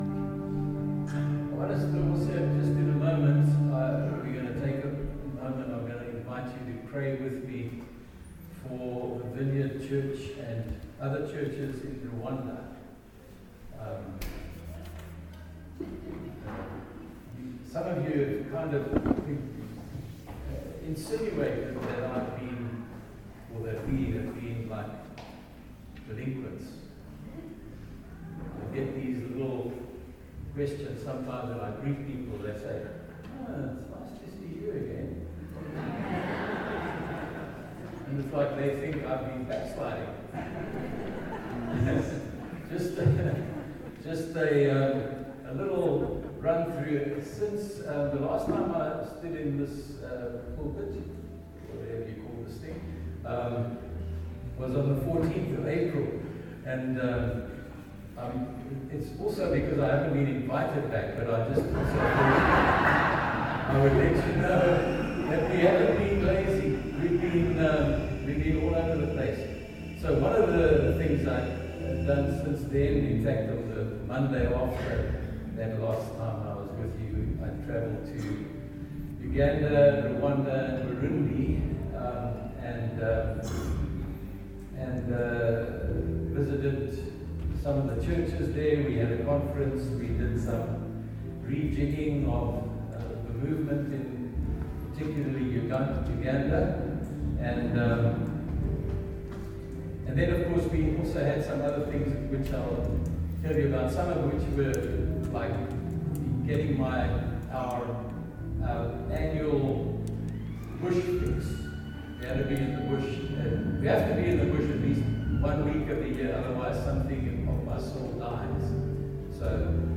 Sunday Service – 18 August
Sermons